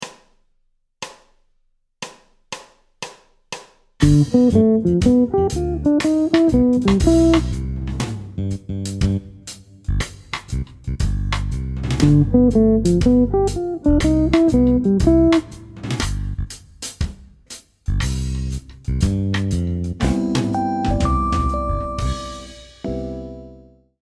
Motif Jazz : (E2) Chromatismes ascendants continus
La phrase utilise sur la dominante le motif de Triade 3 1 7 5. En seconde mesure, sur C∆, la Tierce est visée par une montée chromatique.
Phrase-05-V7-I-en-Maj.mp3